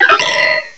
cry_not_pumpkaboo.aif